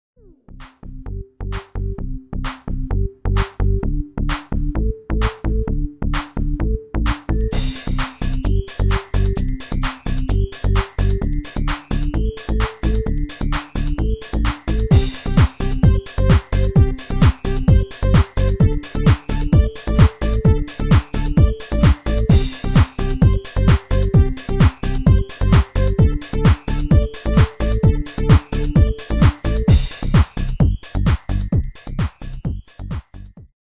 Demo/Koop midifile
U koopt een GM-Only midi-arrangement inclusief:
Demo's zijn eigen opnames van onze digitale arrangementen.